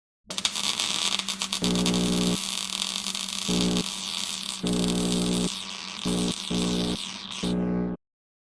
Pulsed Short Circut
SFX
yt_ntab6xHKNIk_pulsed_short_circut.mp3